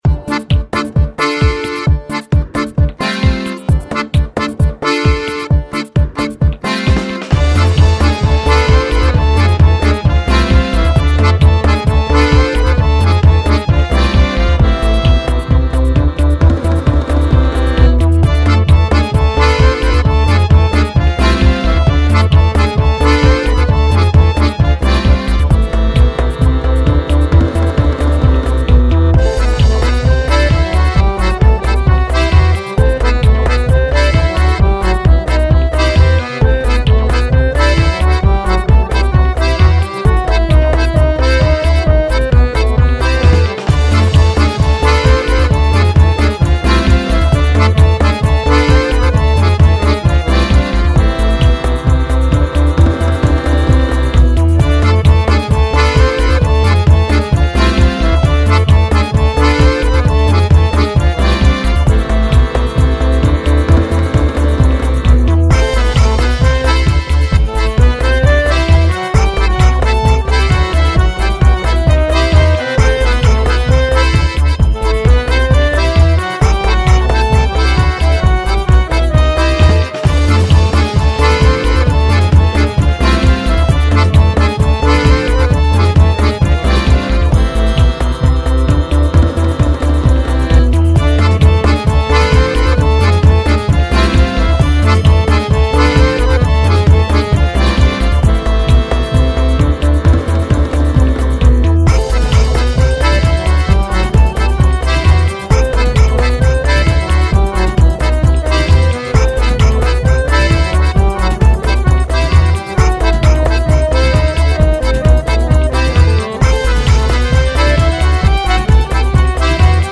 Largo [40-50] joie - accordeon - festif - accordeon - danse